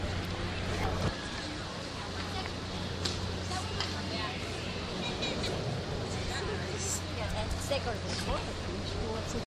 描述：国家广场上的雕塑花园用DS40录制，因为左边的麦克风神秘地停止工作，在Wavosaur中被抢救为单声道录音